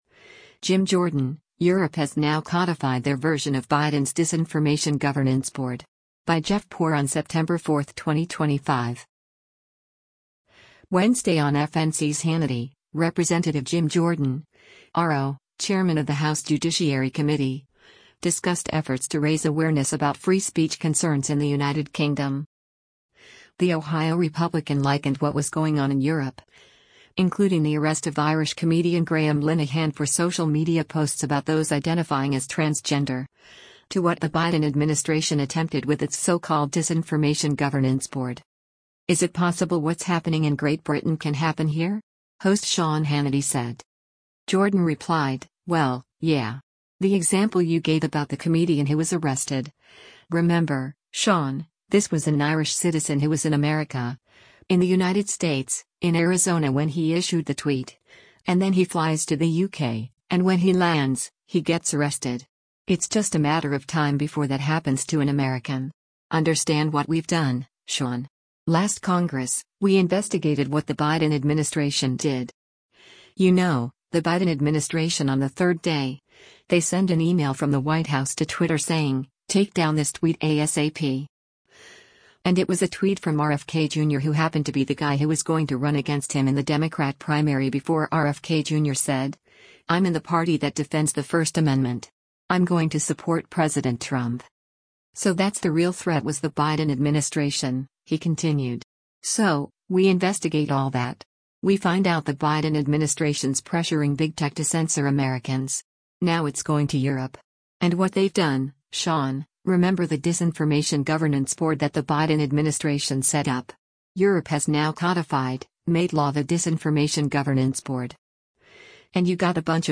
Wednesday on FNC’s “Hannity,” Rep. Jim Jordan (R-OH), chairman of the House Judiciary Committee, discussed efforts to raise awareness about free speech concerns in the United Kingdom.
“Is it possible what’s happening in Great Britain can happen here?” host Sean Hannity said.